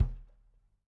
kick1.mp3